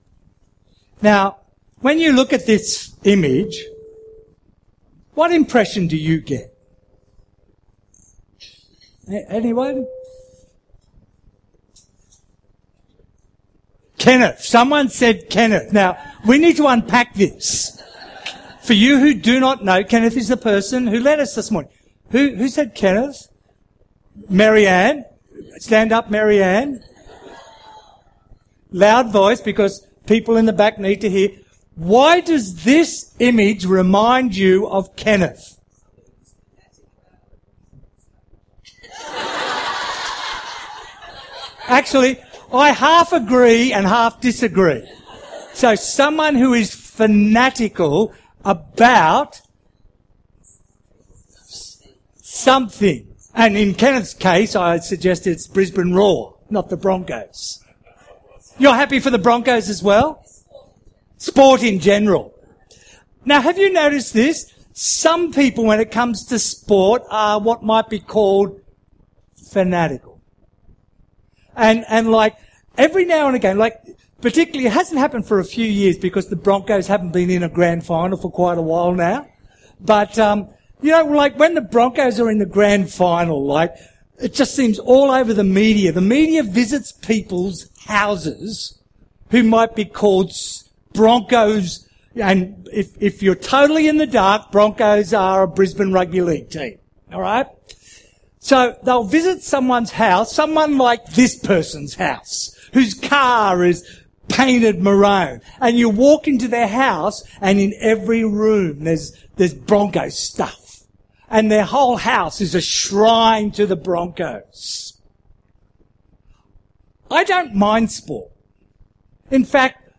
Categories Sermon Tags 2014